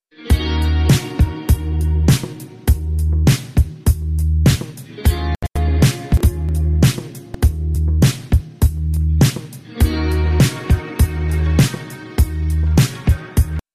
Sporadisches Stottern
Habe mal einen Audiotest angehangen. Das Stottern ist sowohl lokal, als auch auf dem Stream hörbar.
Besoders ist aber, dass es sich bei mir nicht um ein Stottern im Sinne eines Loops durch ein fehlendes Buffern (wie bereits bei anderen im Forum beschrieben), sondern um Pausen, Stottern und leichtes Knacksen handelt.
Die drei Dinge auf einmal klingt jetzt mehr als es ist, es ist ja vielmehr eine Mischung (wie im Demo zu hören).